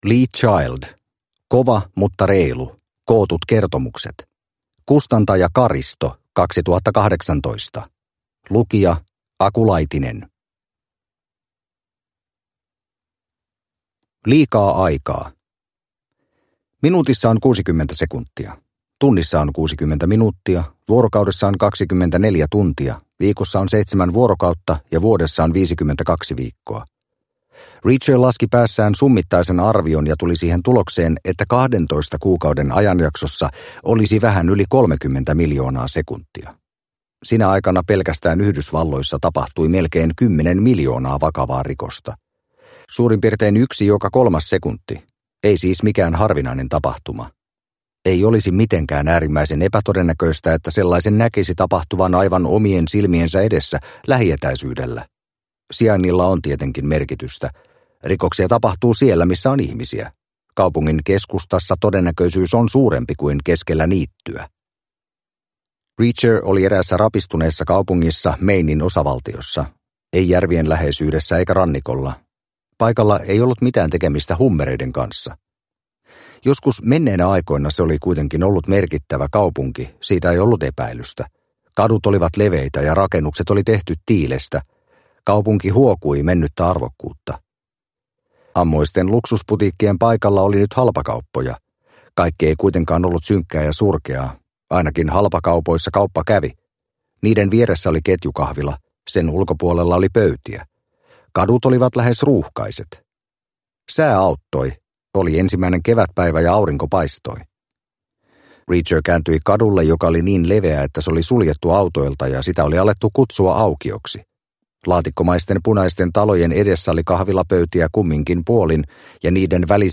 Kova mutta reilu – Ljudbok – Laddas ner